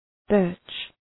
Shkrimi fonetik {bɜ:rtʃ}